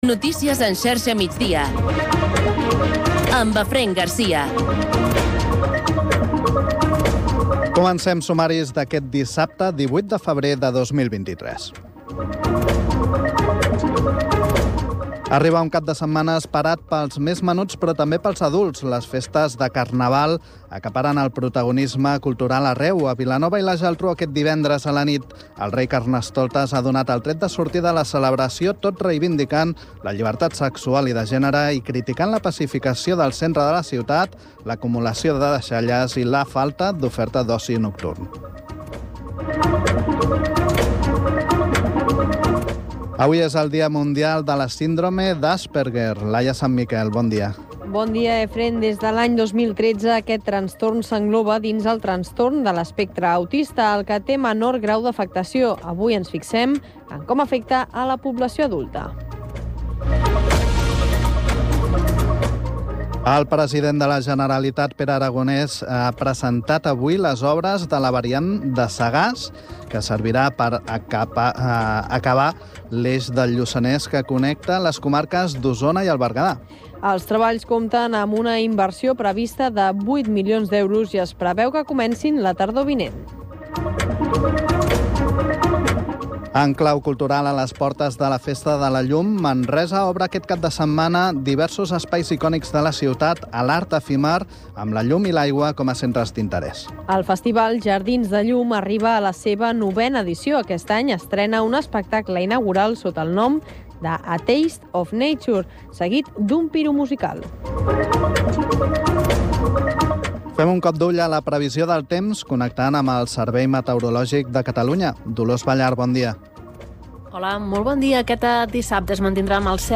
Informatiu radiofònic que dóna prioritat a l’actualitat local.